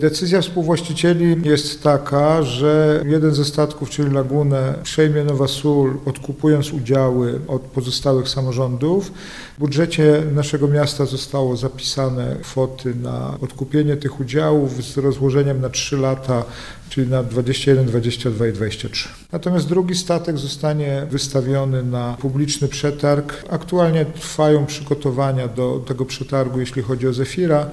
– Laguna zostanie w Nowej Soli, a Zefir pójdzie pod młotek – powiedział Jacek Milewski, prezydent miasta: